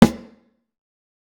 TC SNARE 17.wav